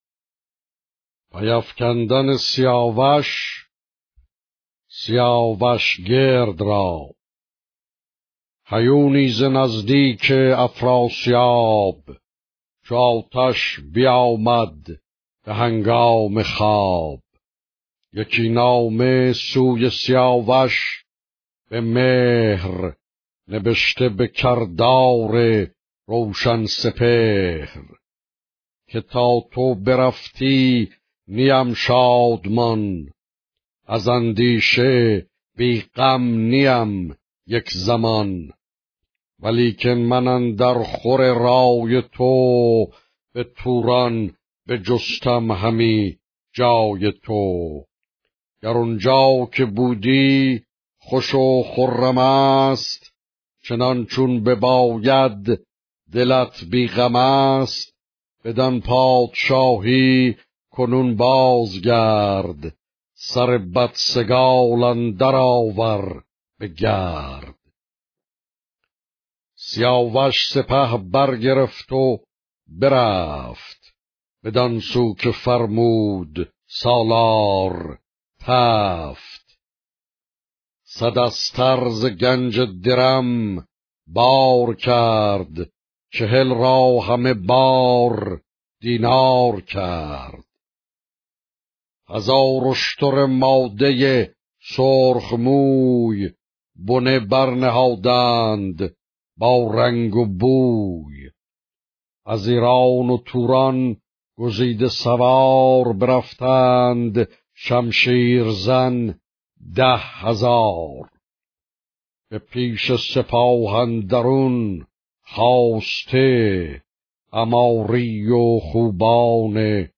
شاهنامه با صدای استاد کزازی - قسمت شصت و نهم - ویکی شاهنامه